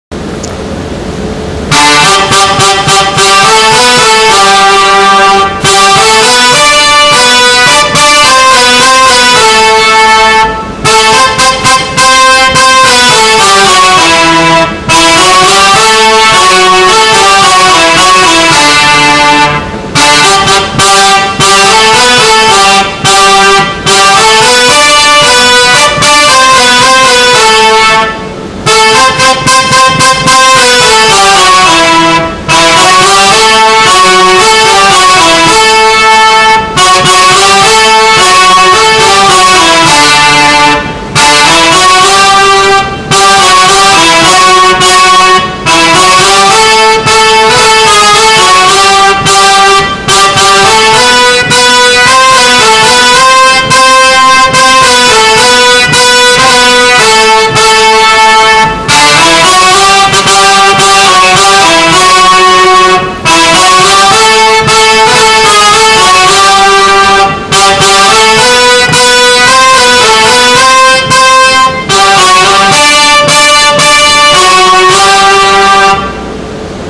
Main Melody /
main_melody.mp3